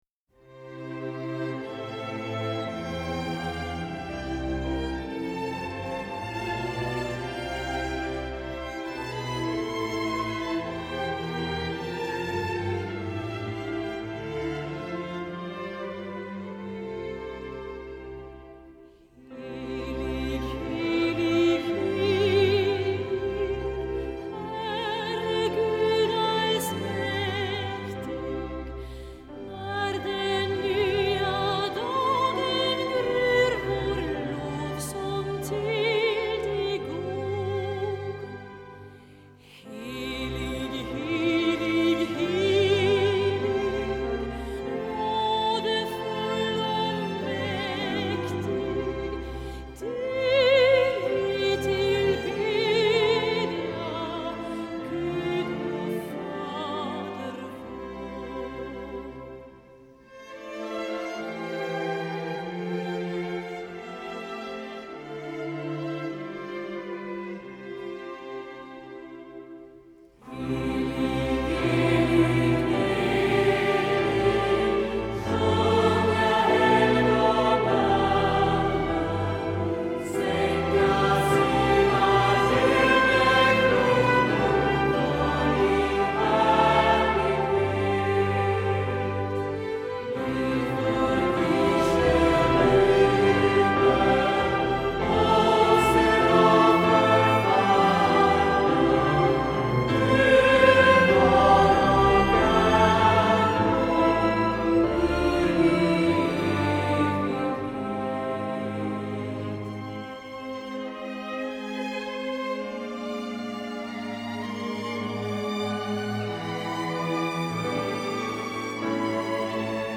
Stråk-,intrumental och vokalarrangemang.